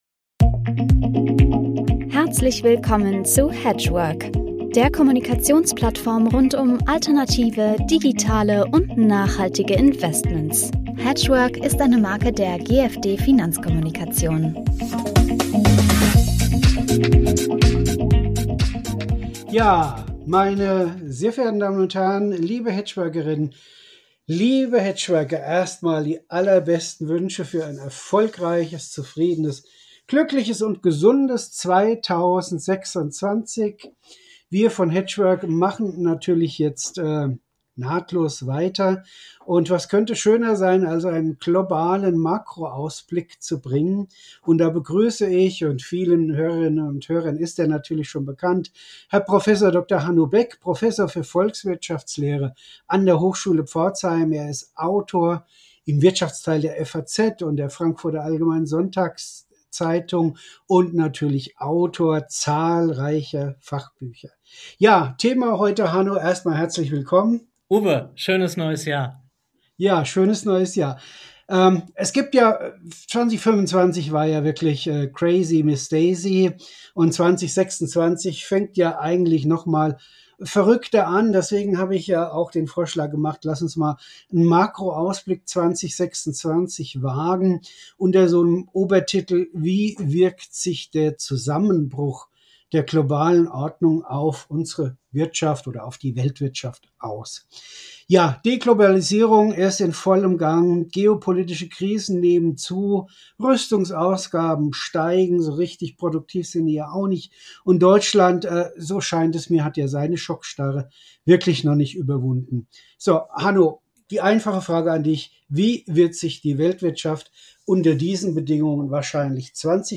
Hedgework-Talk rund um alternative, digitale und nachhaltige Investments Podcast